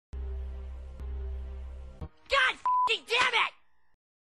south-park-cartman-damn-it.mp3